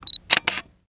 menu-direct-click.wav